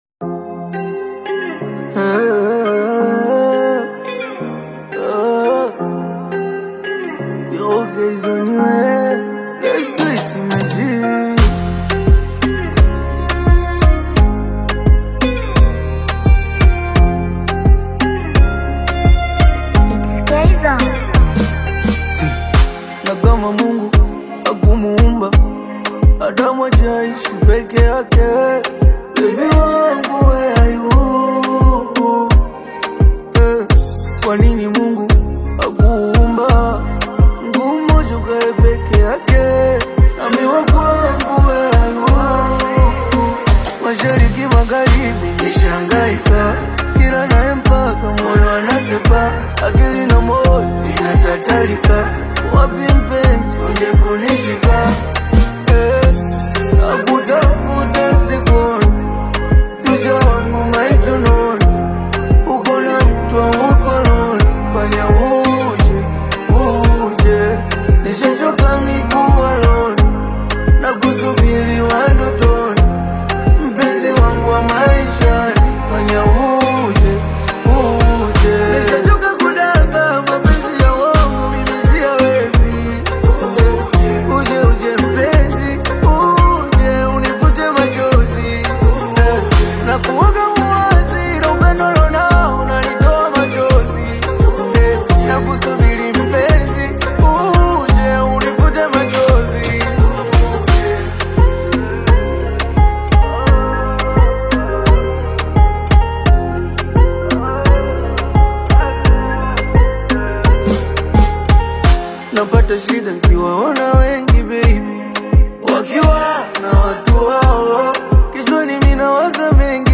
soulful Tanzanian love single
Genre: Bongo Flava